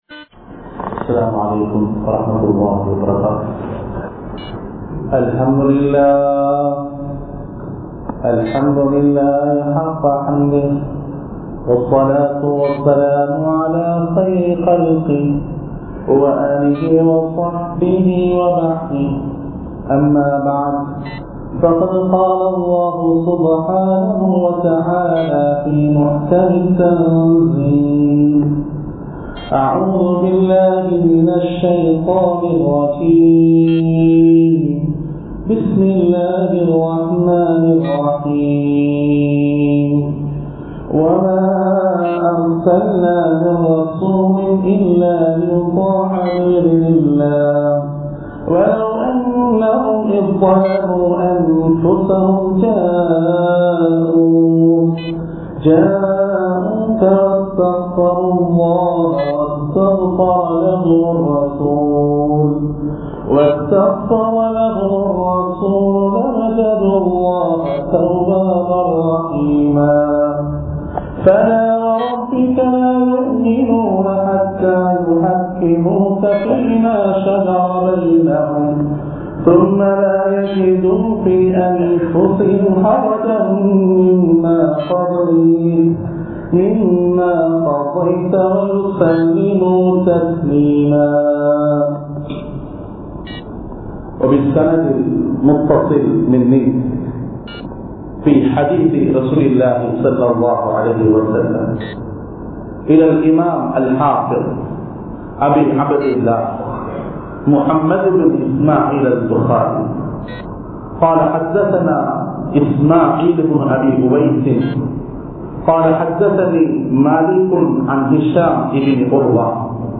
Hathees Thohuppin Thoattram (ஹதீஸ் தொகுப்பின் தோற்றம்) | Audio Bayans | All Ceylon Muslim Youth Community | Addalaichenai
Colombo 06,Kirulapana, Thaqwa Jumua Masjith